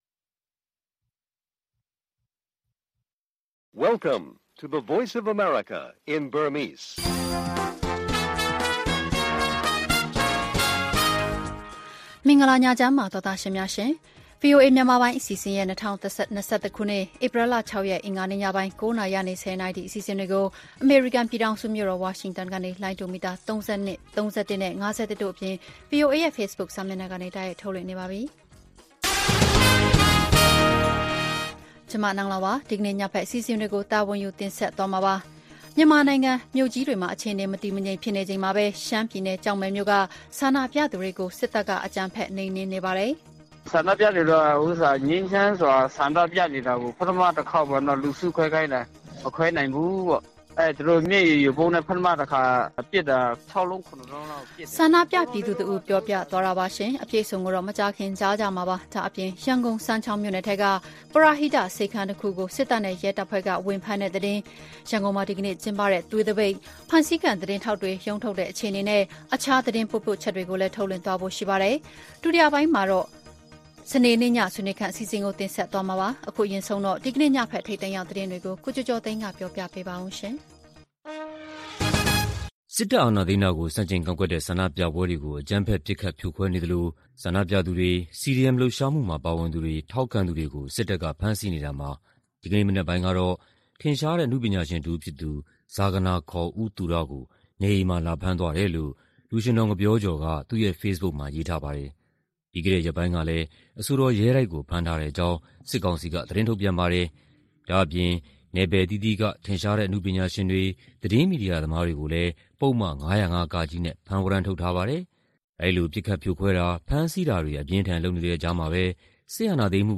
မြန်မာနိုင်ငံက ဒီကနေ့လူထုဆန္ဒပြပွဲနဲ့ ရဲတွေရဲ့လုပ်ရပ် နောက်ဆုံးအခြေအနေများ၊ အင်္ဂါည ဆွေးနွေးခန်း အပတ်စဉ်ကဏ္ဍတွေနဲ့အတူ ည ၉း၀၀ - ၁၀း၀၀ ရေဒီယိုအစီအစဉ်